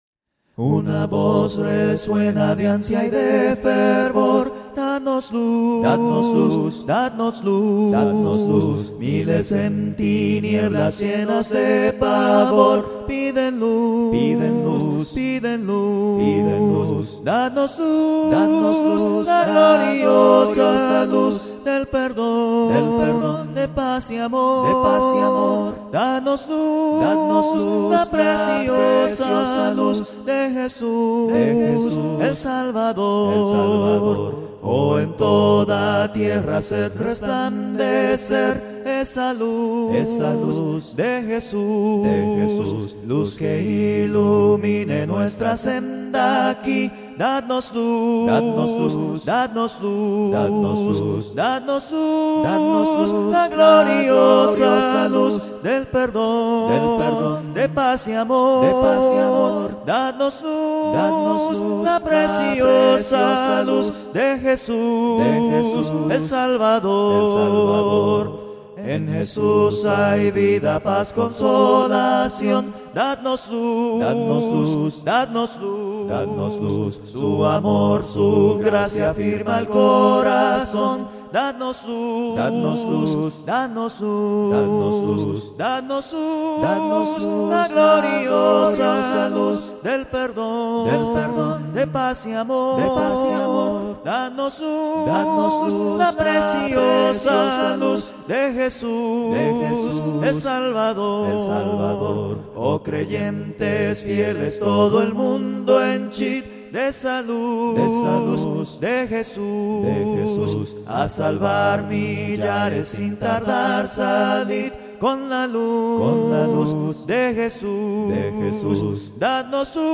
Cánticos Cristianos A Cappella, Gratis
Con Múltiples Voces: